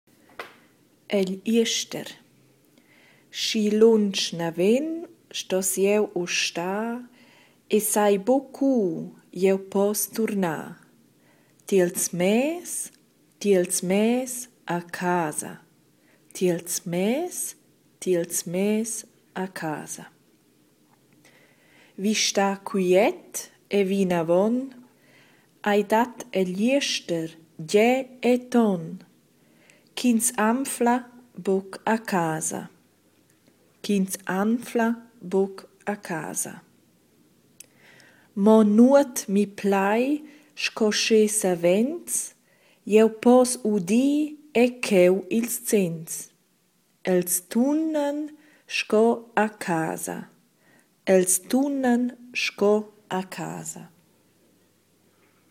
14 Egl jester // Aussprache